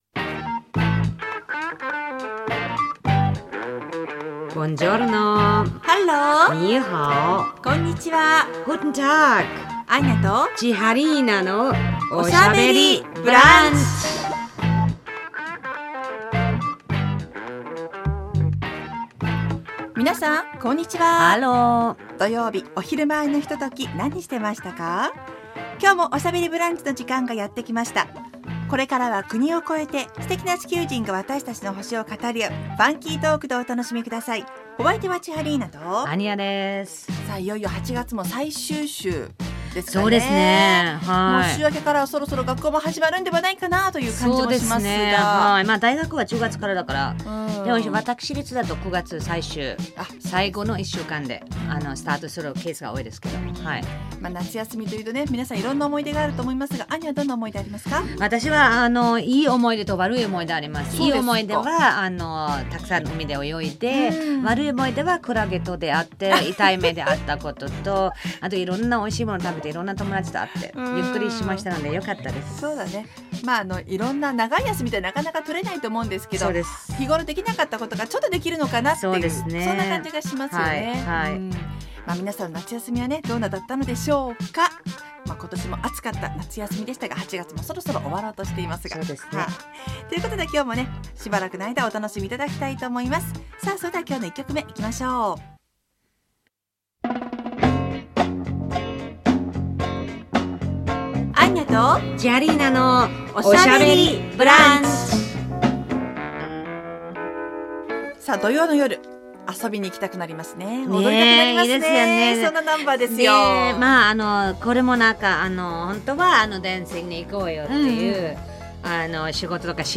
放送された内容を一部編集してお送りします。